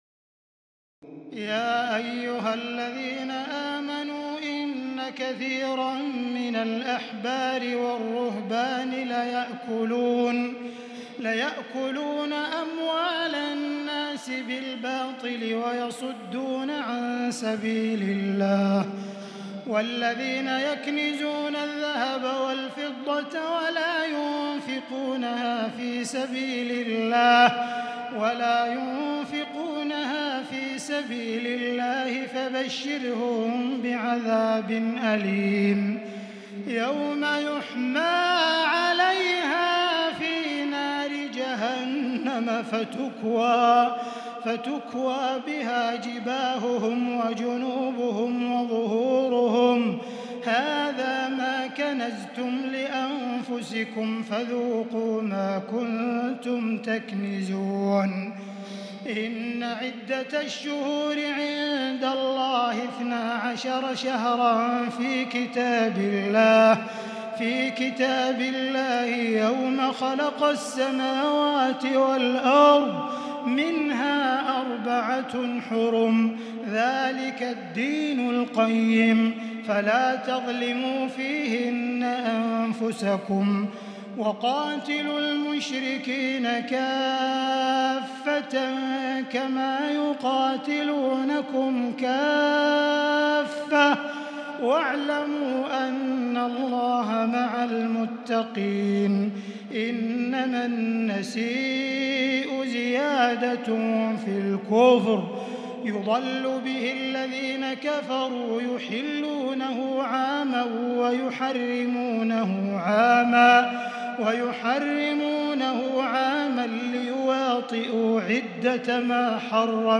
تراويح الليلة التاسعة رمضان 1438هـ من سورة التوبة (34-93) Taraweeh 9 st night Ramadan 1438H from Surah At-Tawba > تراويح الحرم المكي عام 1438 🕋 > التراويح - تلاوات الحرمين